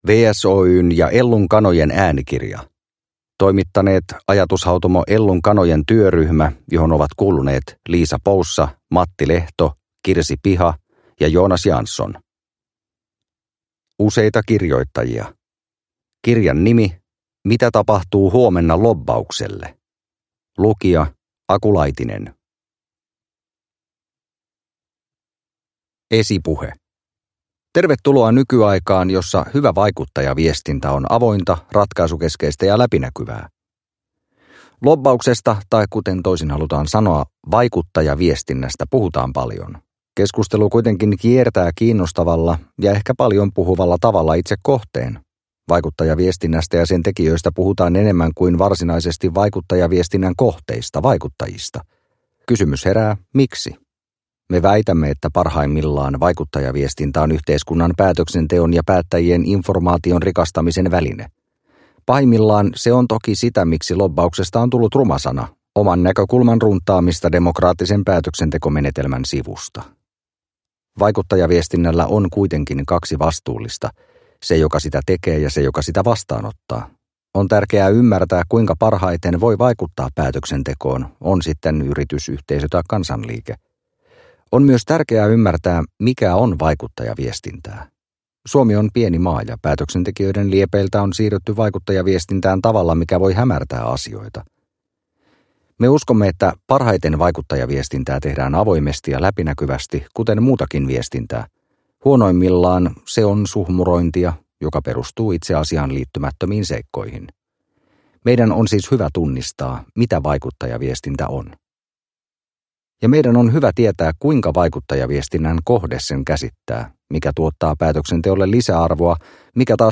Mitä tapahtuu huomenna lobbaukselle? – Ljudbok – Laddas ner